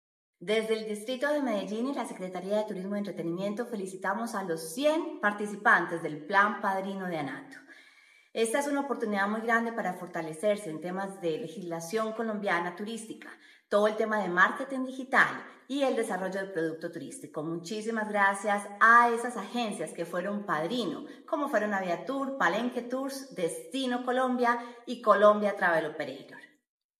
Declaraciones de la secretaria de Turismo y Entretenimiento, Ana María López Acosta
Declaraciones-de-la-secretaria-de-Turismo-y-Entretenimiento-Ana-Maria-Lopez-Acosta.mp3